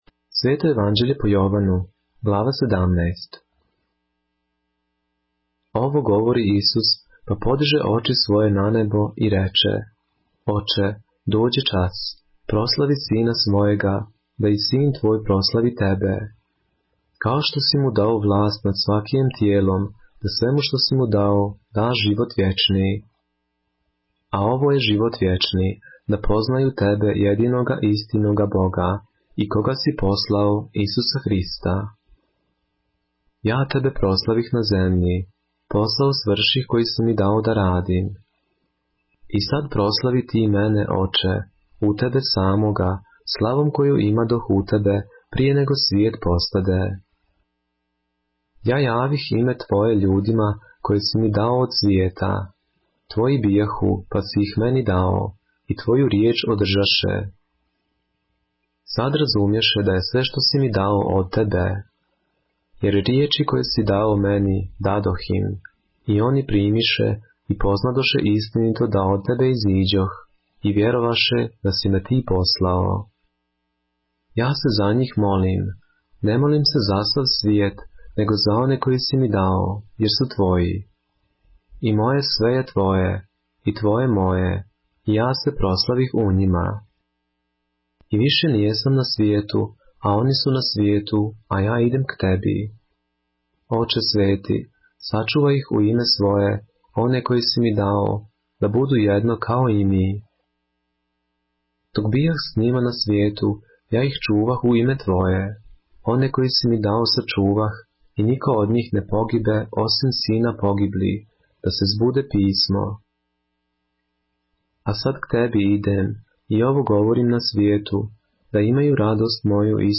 поглавље српске Библије - са аудио нарације - John, chapter 17 of the Holy Bible in the Serbian language